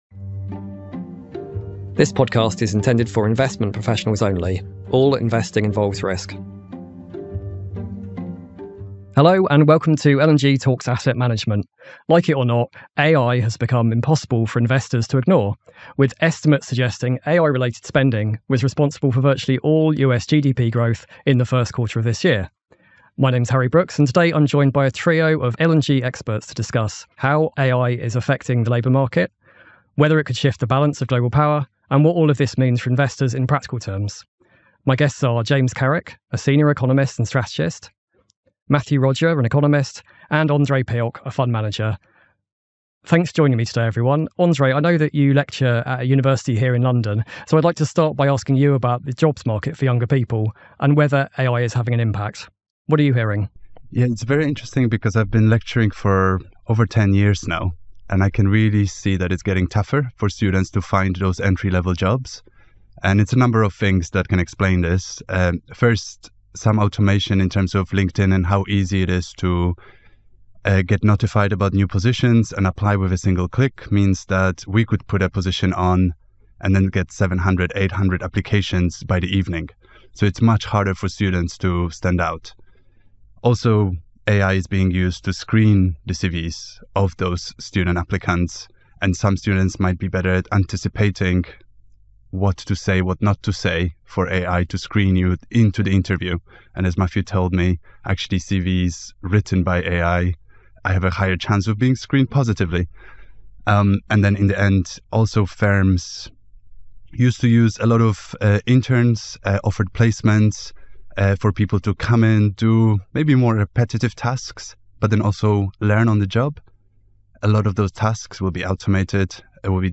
In this week’s episode, three L&G experts share their insights.